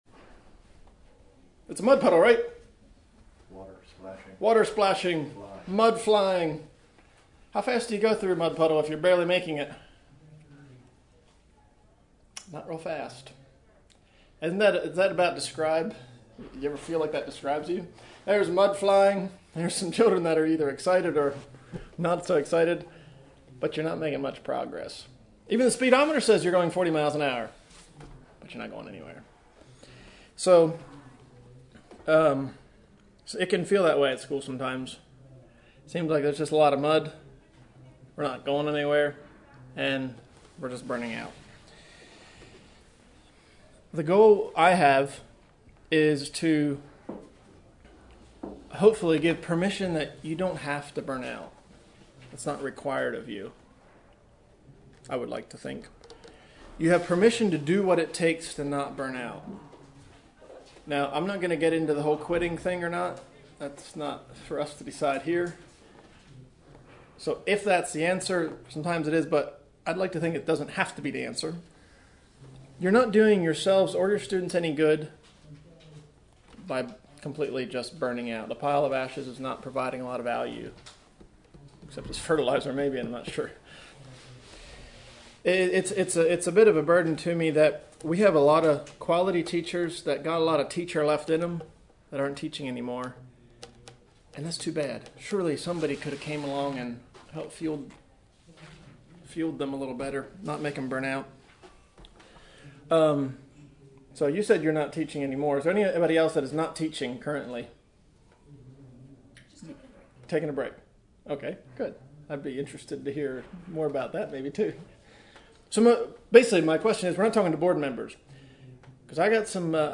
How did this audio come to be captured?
2025 Western Fellowship Teachers Institute 2024 Avoiding Teacher Burnout 00:00